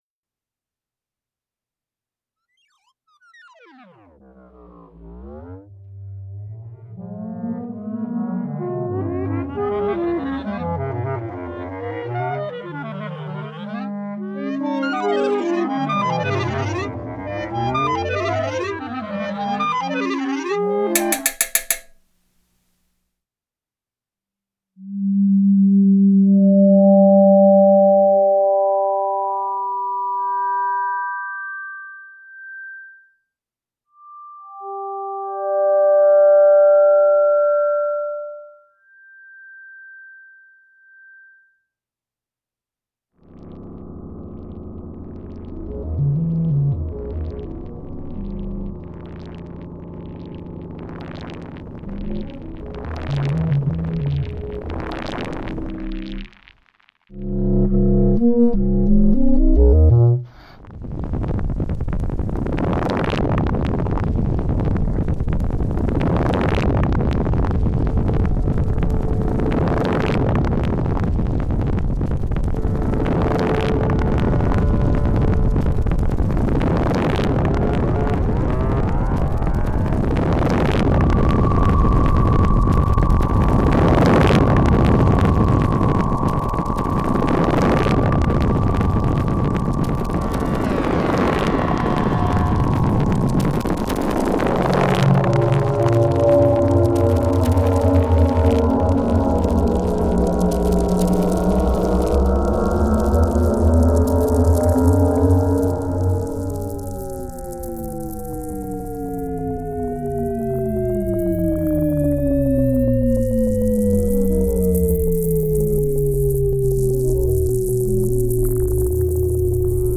clarinetto
Clarinetto is a quadraphonic electroacoustic composition. A stereo mix of the work can be heard here.